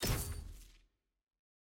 sfx-jfe-ui-warning-exit.ogg